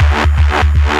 Dirty Bass.wav